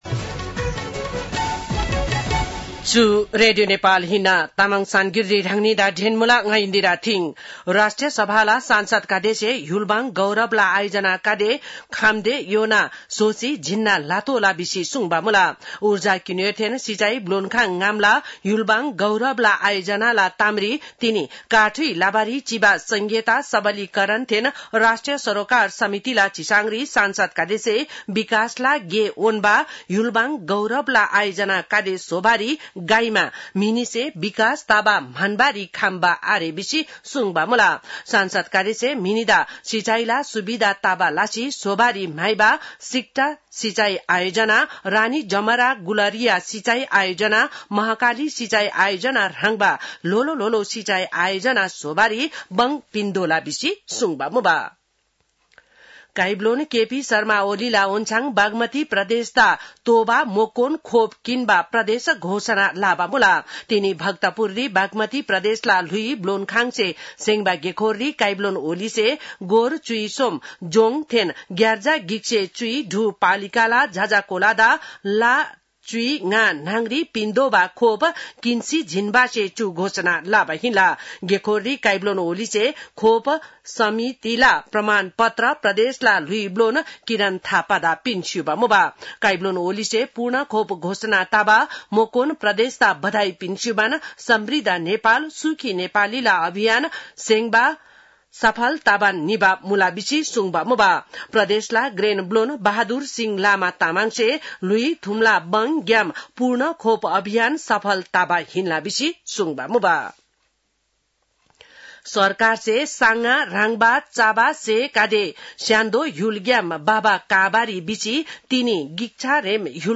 तामाङ भाषाको समाचार : १६ साउन , २०८२
Tamang-news-4-16.mp3